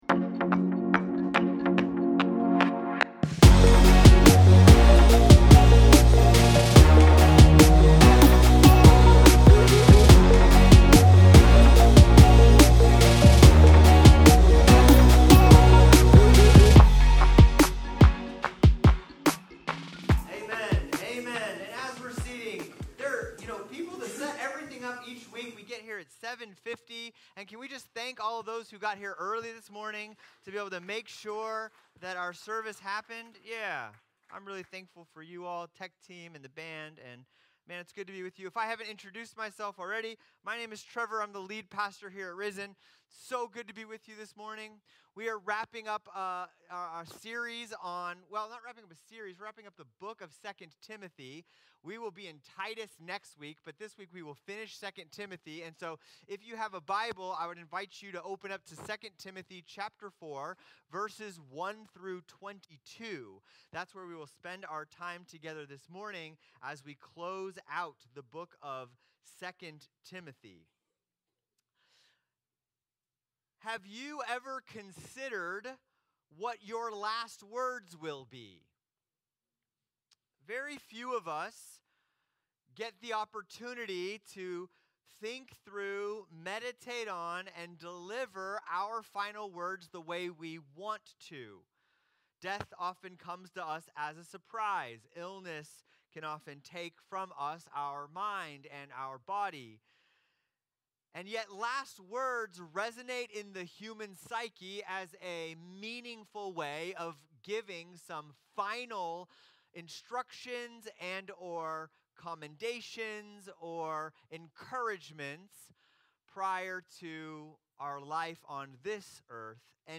Recent sermons from Risen Church.